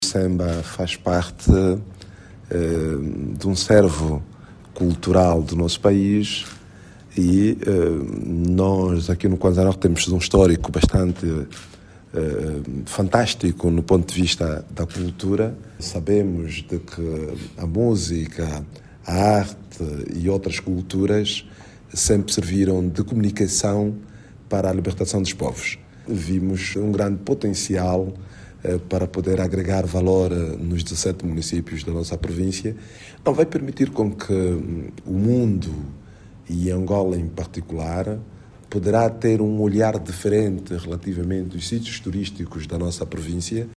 O governador da província João Diogo Gaspar, sublinha a importância dos projectos, na promoção do turismo cultural na região.